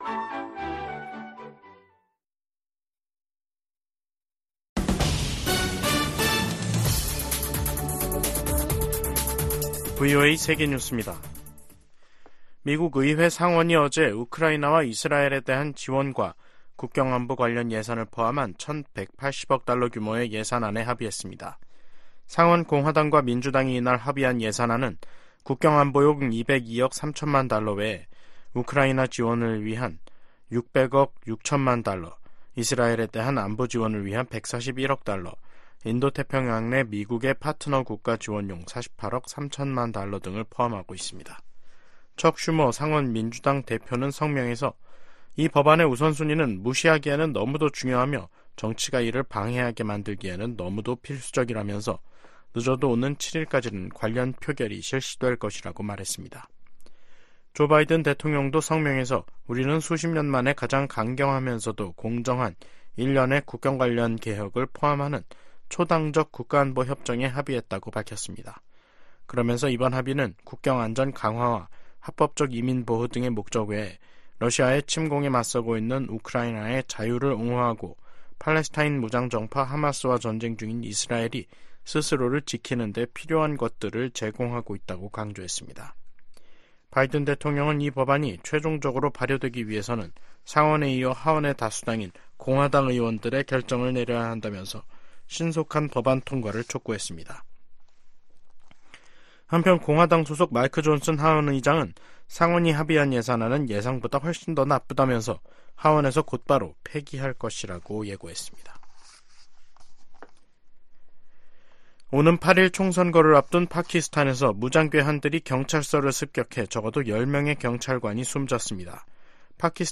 VOA 한국어 간판 뉴스 프로그램 '뉴스 투데이', 2024년 2월 5일 2부 방송입니다. 북한은 순항미사일 초대형 전투부 위력 시험과 신형 지대공 미사일 시험발사를 지난 2일 진행했다고 대외 관영 ‘조선중앙통신’이 다음날 보도했습니다. 미국 정부는 잇따라 순항미사일을 발사하고 있는 북한에 도발을 자제하고 외교로 복귀하라고 촉구했습니다. 미국 정부가 미국내 한인 이산가족과 북한 가족들의 정보를 담은 기록부를 구축하도록 하는 법안이 하원에서 발의됐습니다.